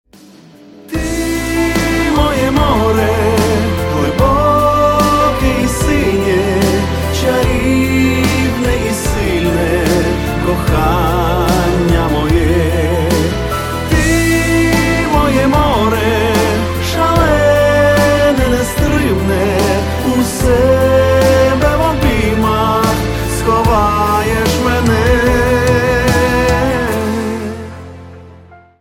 • Качество: 128, Stereo
украинские
баллада
лиричные